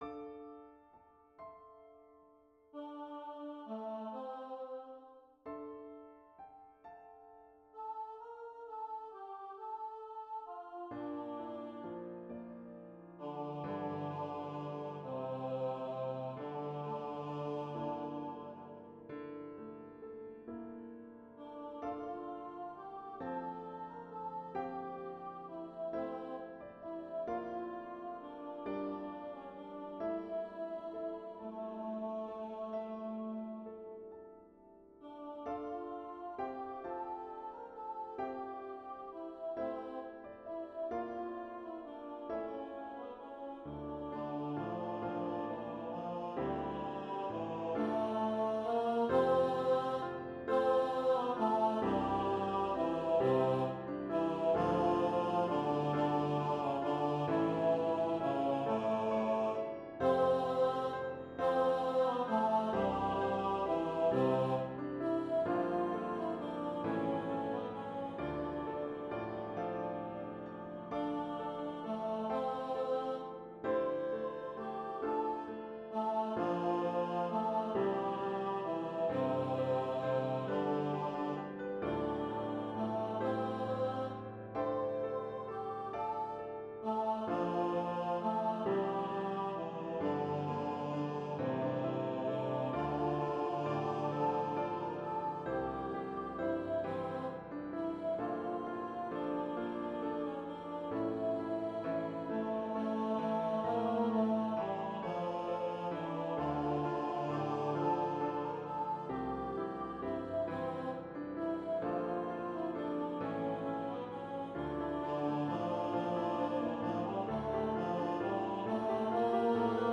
Soprano/Tenor Duet and Piano